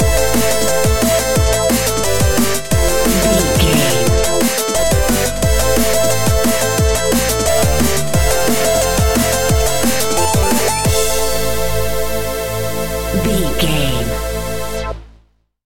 Epic / Action
Fast paced
Aeolian/Minor
aggressive
dark
driving
intense
futuristic
synthesiser
drum machine
electronic
sub bass
synth leads